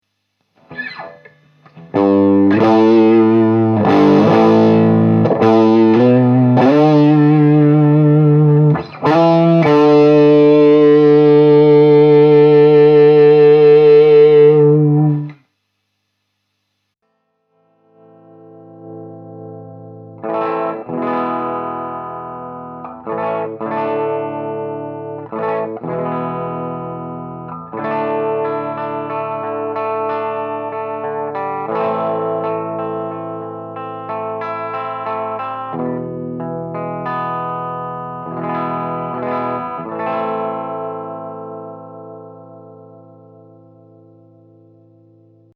Same swirl !!
Re: Your sIII Clone Swirling sound:
I cant detect any phasing sort of sound. I hear a tiny buzz - Maybe but overall the sound is fine. To me I think it sounds pretty good.